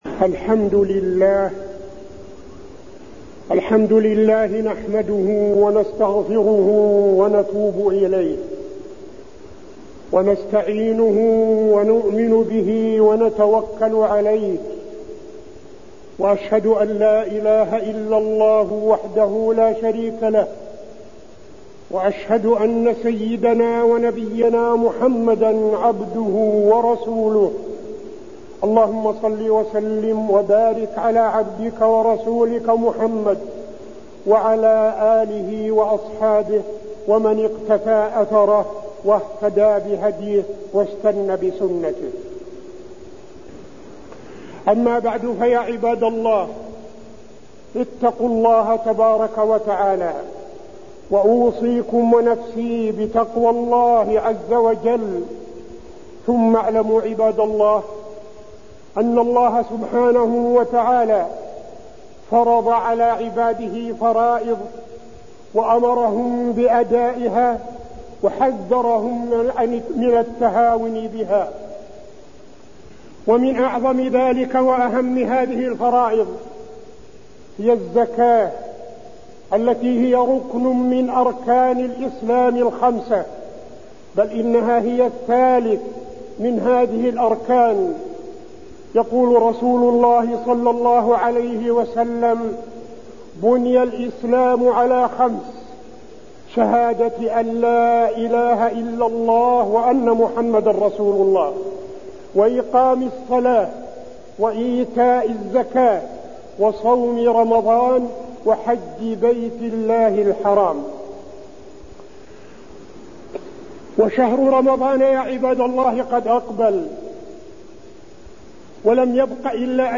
تاريخ النشر ٢١ شعبان ١٤٠٨ هـ المكان: المسجد النبوي الشيخ: فضيلة الشيخ عبدالعزيز بن صالح فضيلة الشيخ عبدالعزيز بن صالح الزكاة وأحكامها1